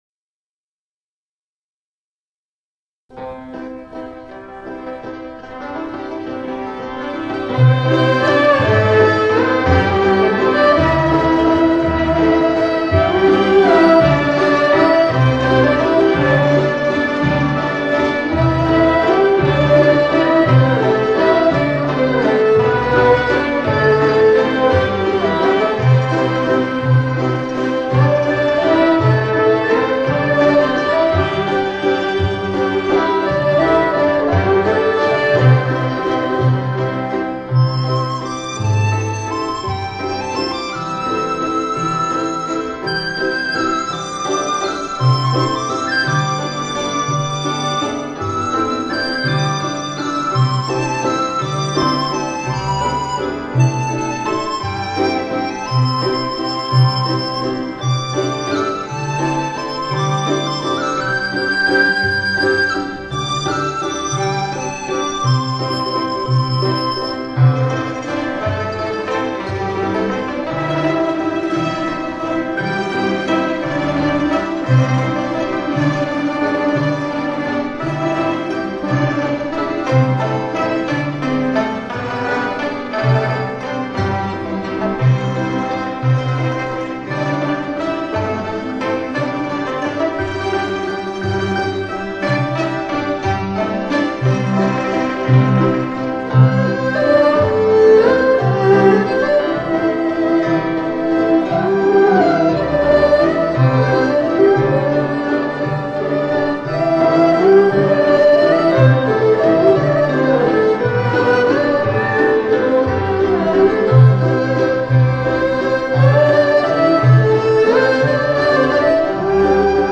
民乐合奏